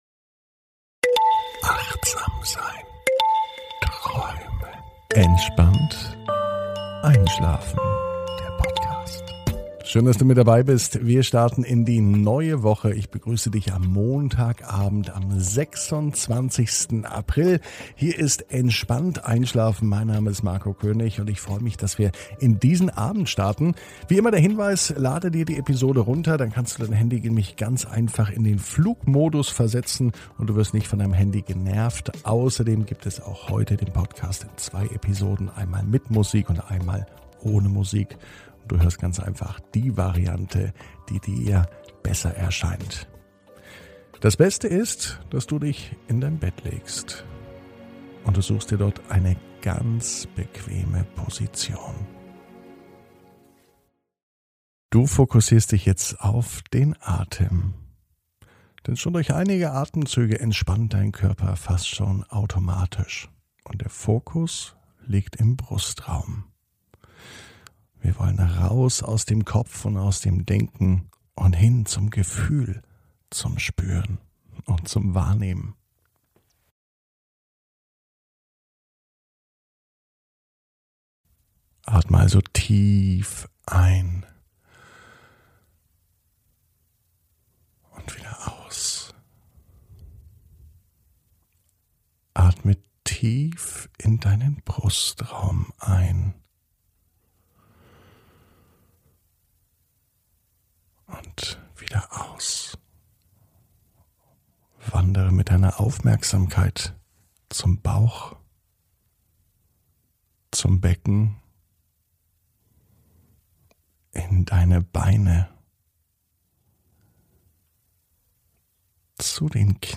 (ohne Musik) Entspannt einschlafen am Montag, 26.04.21 ~ Entspannt einschlafen - Meditation & Achtsamkeit für die Nacht Podcast